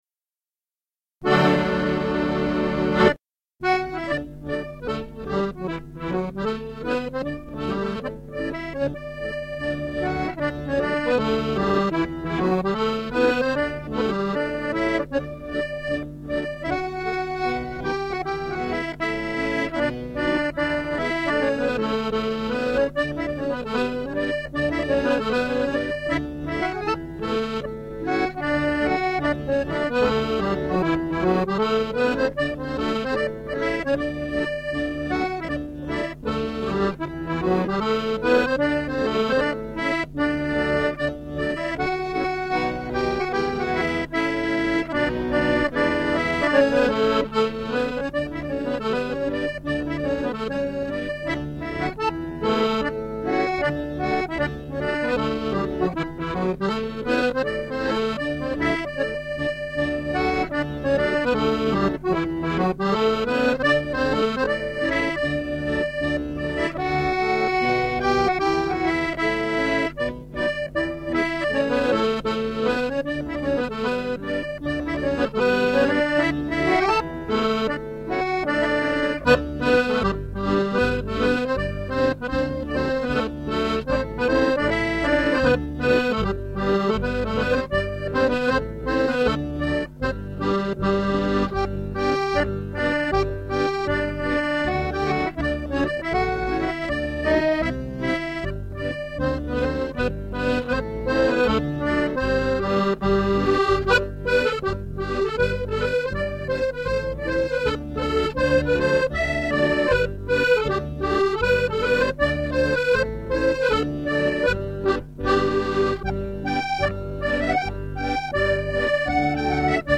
Music - 32 bar hornpipes - the whole dance is done to a step-hop rhythm
audio/6+ x 32 hornpipes.mp3